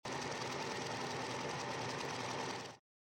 wheel-spin.mp3